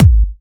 VEC3 Bassdrums Trance 32.wav